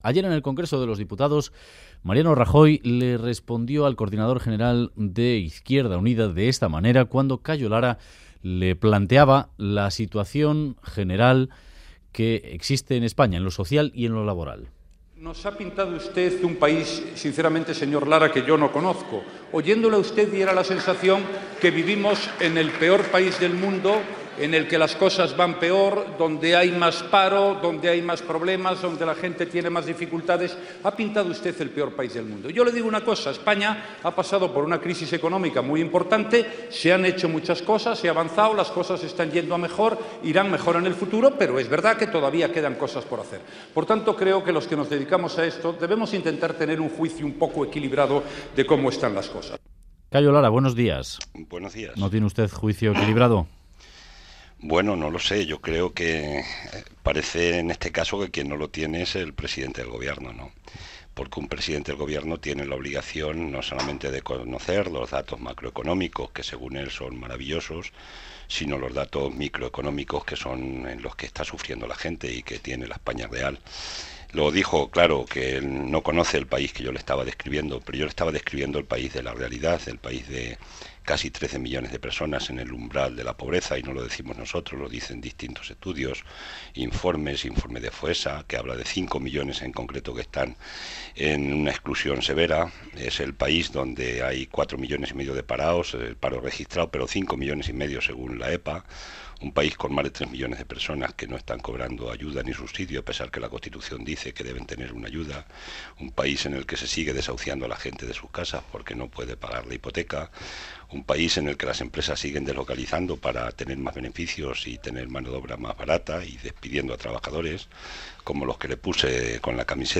IU : En Radio Euskadi, el coordinador federal de Izquierda Unida, Cayo Lara, ha dicho que es "incomprensible" que Podemos no vaya a la unidad de la izquierda.
Radio Euskadi BOULEVARD 'Es incomprensible que Podemos no quiera la unidad de la izquierda' Última actualización: 12/02/2015 10:08 (UTC+1) En entrevista al Boulevard de Radio Euskadi, el coordinador federal de Izquierda Unida, Cayo Lara, ha afirmado que Podemos se equivoca de forma radical y que es "incomprensible" que no vaya a la unidad de la izquierda.